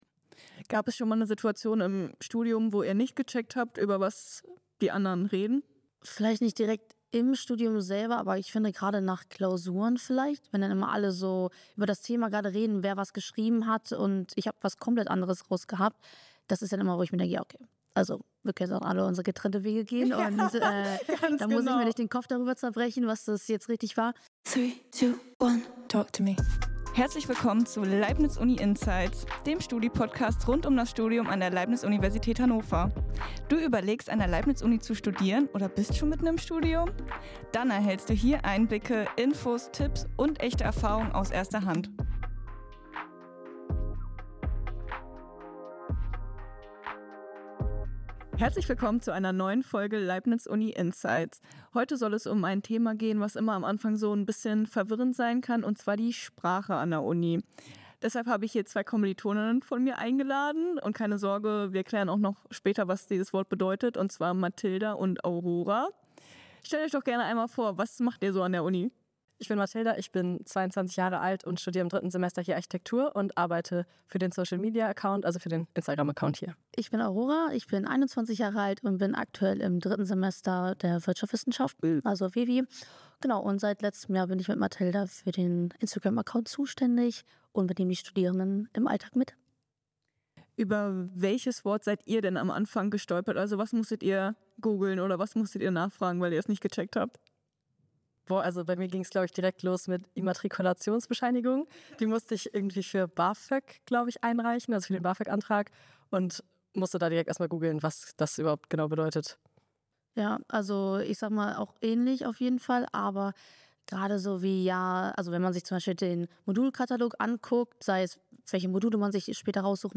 spricht in dieser Folge von Leibniz UNInsights mit zwei Studierenden der Leibniz Universität Hannover